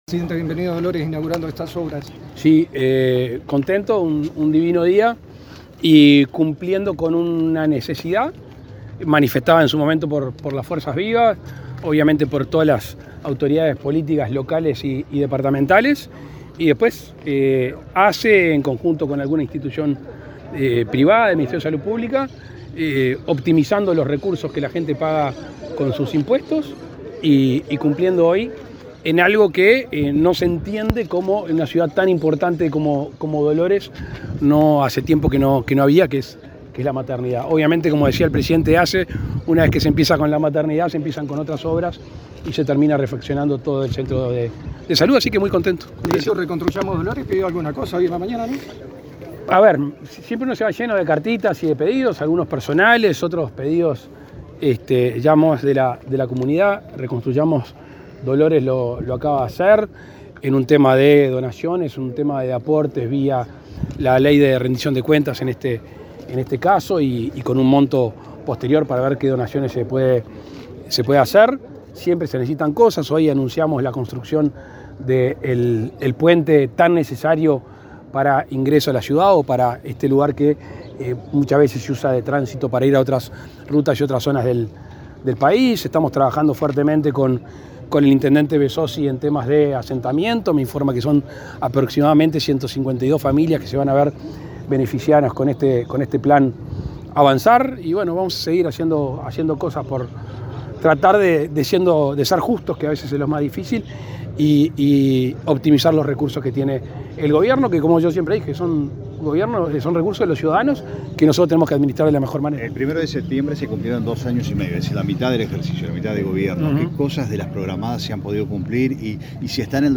Declaraciones del presidente Lacalle Pou a la prensa
Luego dialogó con la prensa.